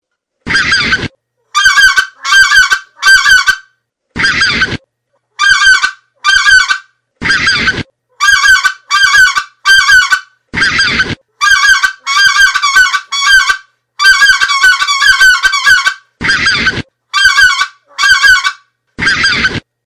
stock redbone double high three note